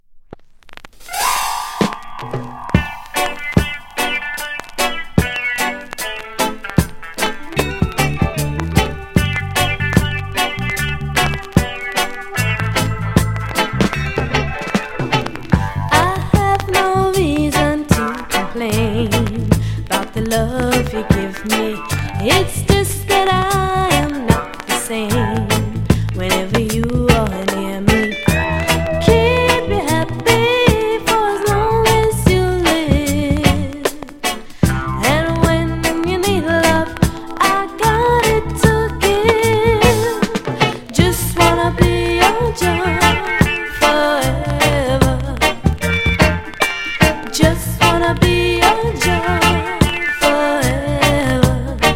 スウィートなヴォーカルにグッと来ちゃうナイス・チューン。